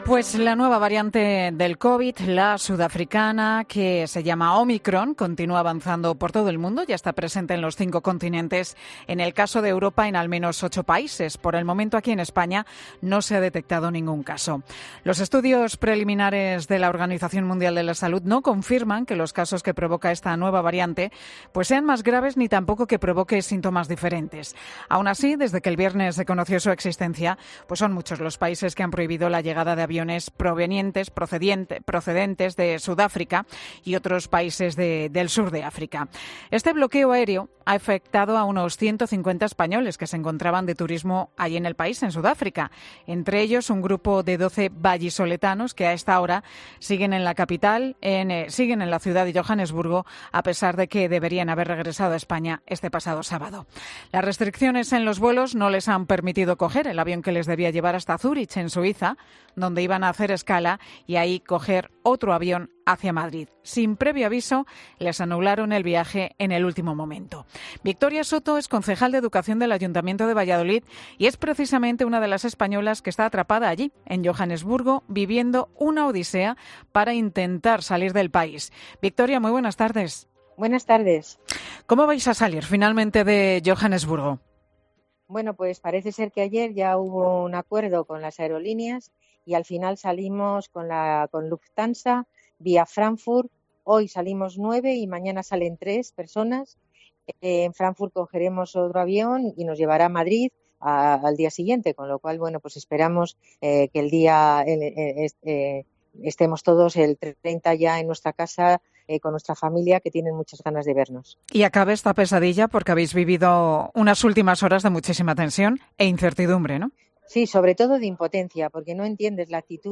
“La aerolínea, como pronto, nos daba vuelos para el día 6 de diciembre. Pero la situación ha mejorado. Del grupo de 12, nueve viajamos hasta Frankfut esta misma tarde. Y los tres restantes vuelan mañana. Desde Frankfurt iremos ya directos a Madrid”, ha explicado en Mediodía COPE Victoria Soto, concejal de Educación del Ayuntamiento de Valladolid y es una de las españolas que está en el aeropuerto de Johannesburgo metida de lleno en esta odisea para intentar salir de Sudáfrica.